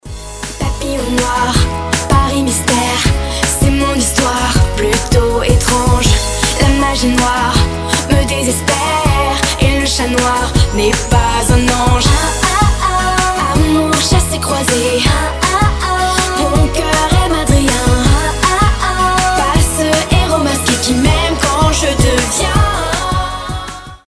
• Качество: 320, Stereo
красивый женский голос
из мультфильмов